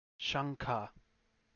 ʃ
ಶಂಖ śankha 'conch shell' German Schweiz